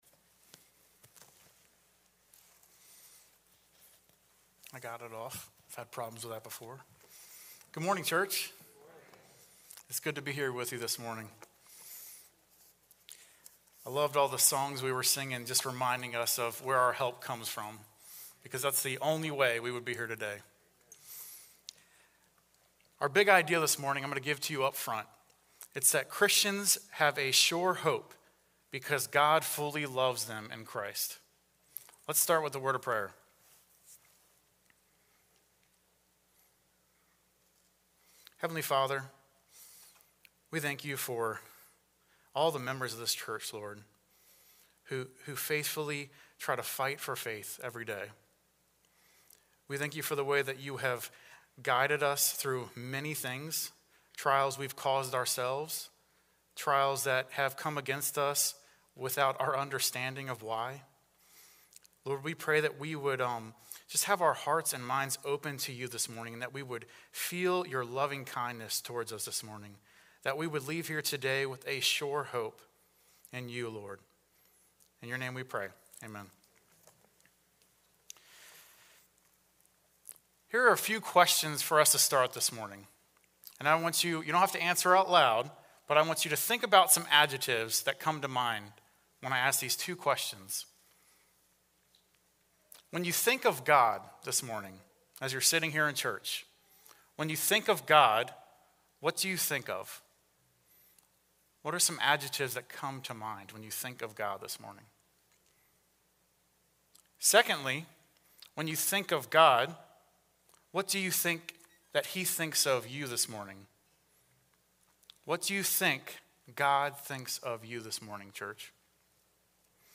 A message from the series "Behold our God!."